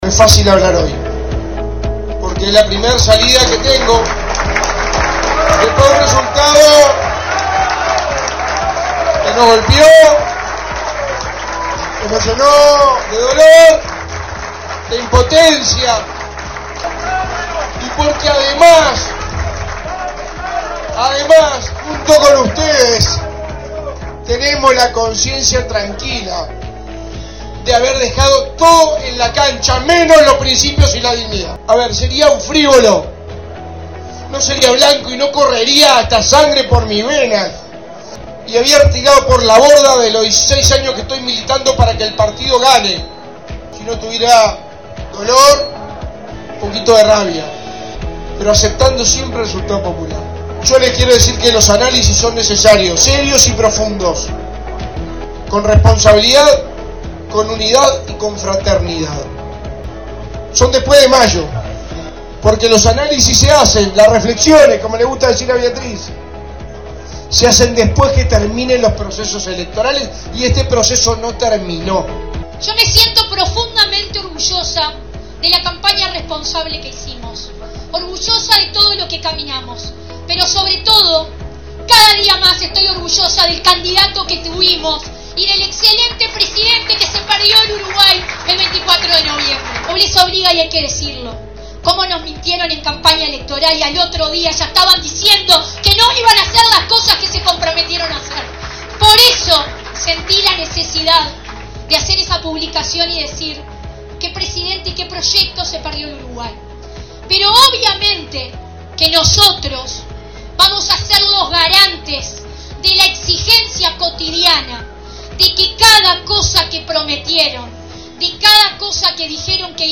Este sábado dirigentes del Partido Nacional del sector D Centro realizaron una actividad para despedir el año y allí por primera vez habló el ex candidato Álvaro Delgado tras la derrota en el balotaje frente a Yamandú Orsi.